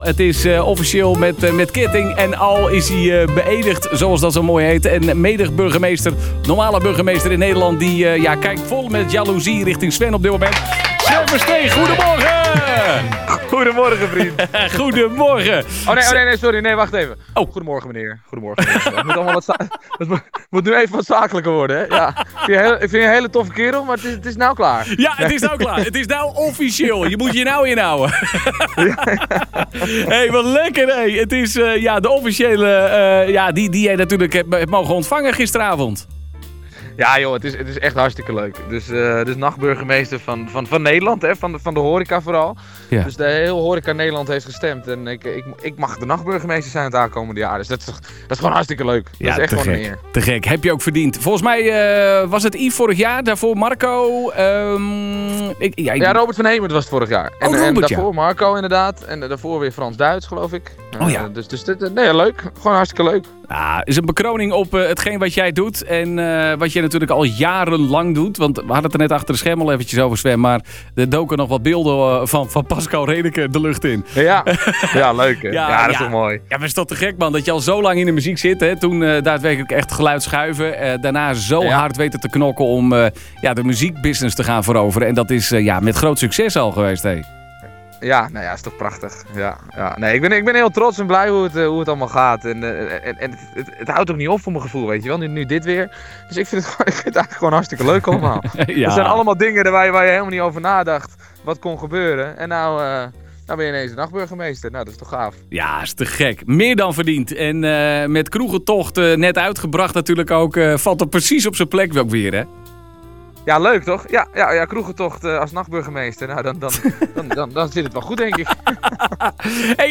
BELLEN